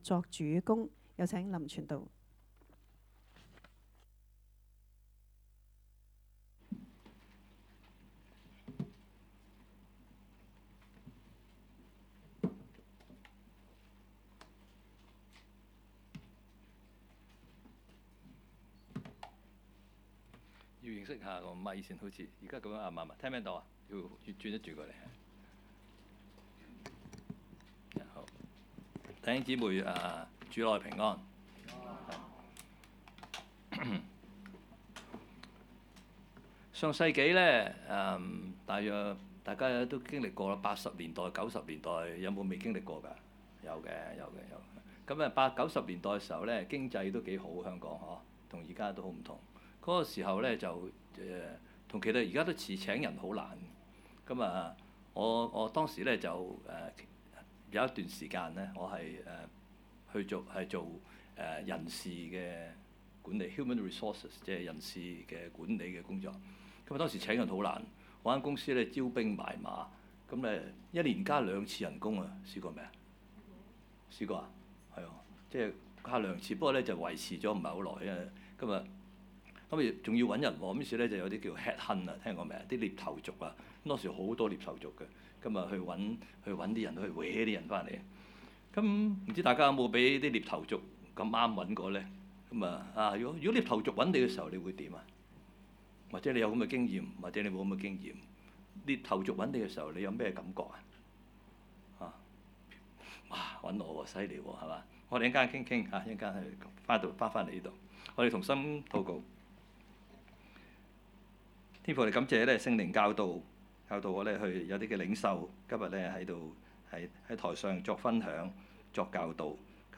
崇拜講道